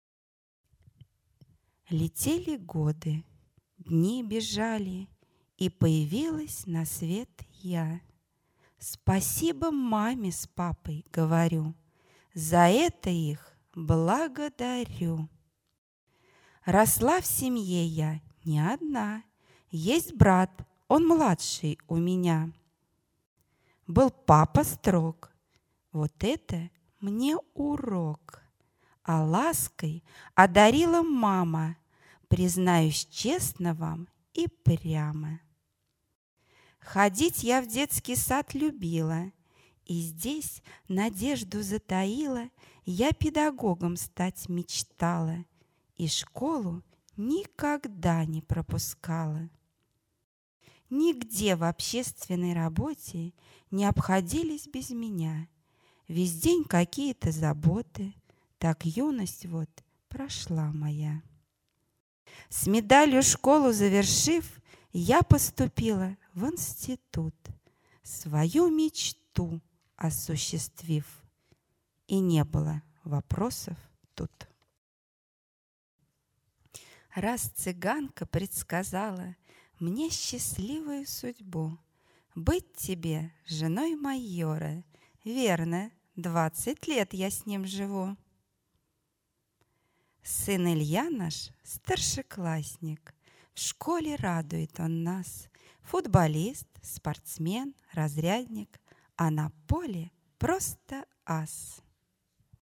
Аудиовизитка (№4) на конкурсе "Учитель года-2011".